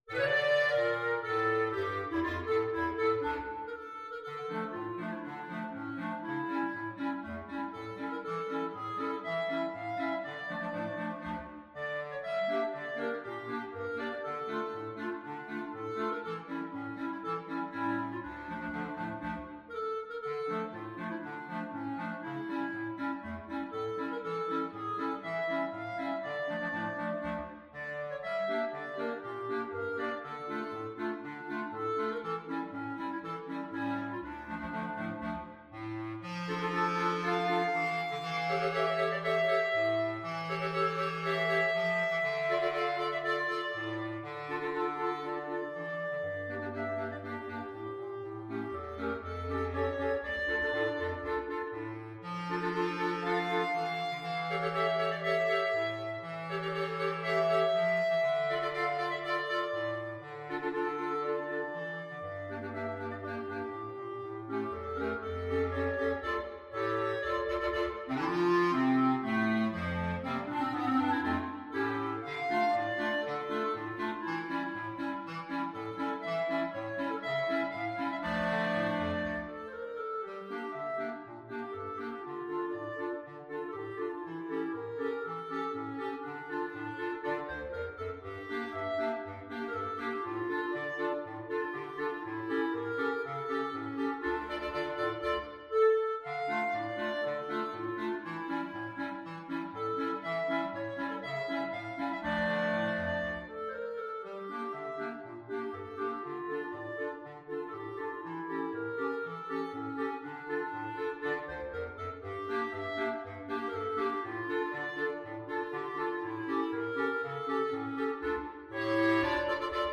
Clarinet 1Clarinet 2Clarinet 3Clarinet 4Bass Clarinet
Allegro = c.120 (View more music marked Allegro)
2/2 (View more 2/2 Music)
Classical (View more Classical Clarinet Choir Music)